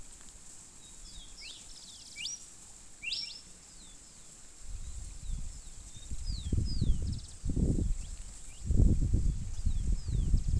Andean Tinamou (Nothoprocta pentlandii)
Spanish Name: Inambú Silbón
Detailed location: Ruta 307 Entre El Infiernillo Y Tafi Del Valle
Condition: Wild
Certainty: Recorded vocal